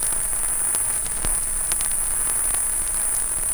Stereo_Test_Tone_03.wav